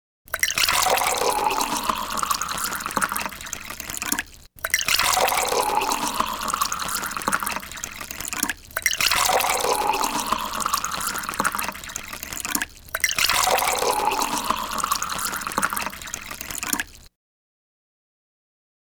SIRVIENDO UNA BEBIDA
Tonos EFECTO DE SONIDO DE AMBIENTE de SIRVIENDO UNA BEBIDA
Sirviendo_una_bebida.mp3